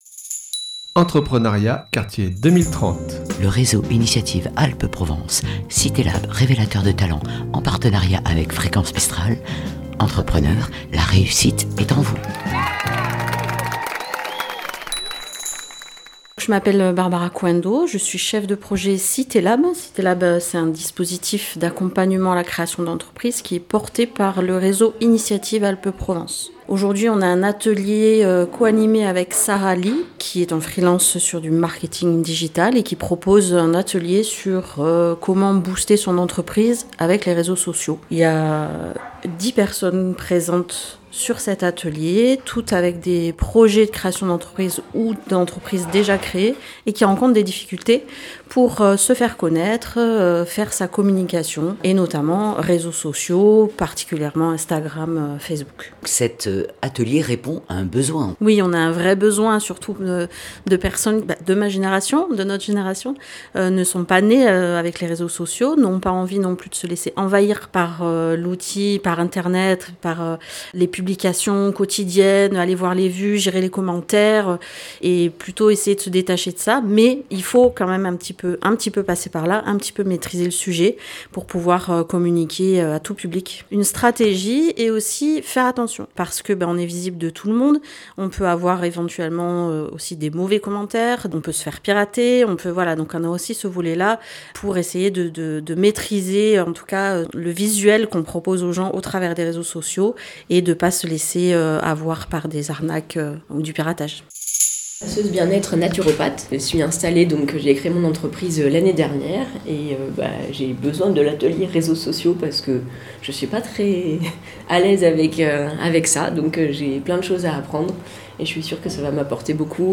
Atelier réseaux sociauxmp3.mp3 (15.5 Mo) Jeudi 4 février 2026, le dispositif CitésLab était présent quartier des Serrets pour un atelier dont l'objectif était de booster son entreprise via les réseaux sociaux.